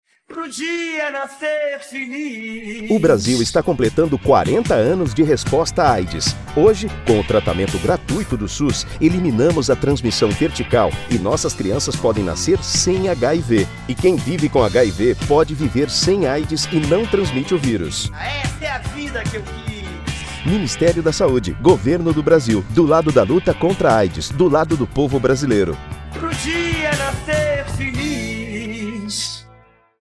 Spot - Áudio - Campanha do Dia Mundial de Luta Contra a Aids - 592kb .mp3 — Ministério da Saúde